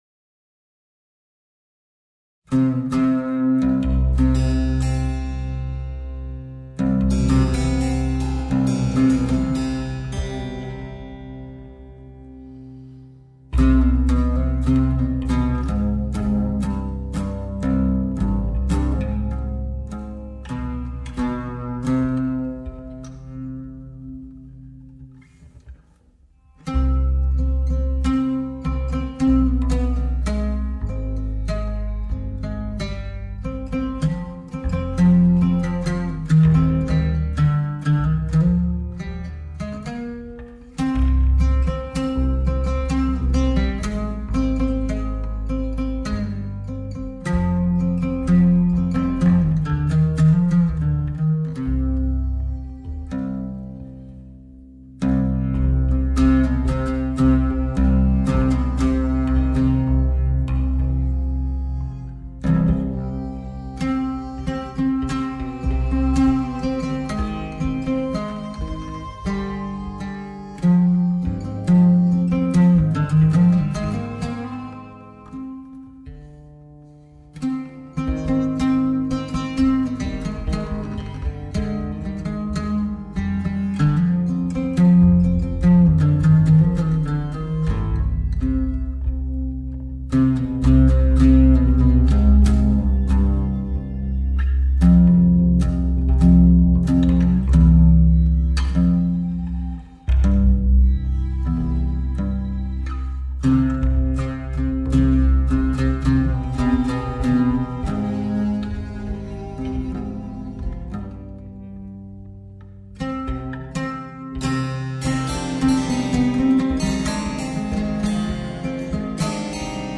The Sukar sounded thin with those factory strings.
This is the same oud recorded using the exact same mics and the same technique:
The touches of YayliTambur samples was played on keyboard using Kontakt 2.
The reverb was Altiverb, which is a great software-only reverb.
A lot of bass had to be filtered off the audio track to prep the video for upload because many people use small speakers on YouTube, and with so much energy in the (to them inaudible) low frequencies, to these viewers the overall volume would seem extremely low.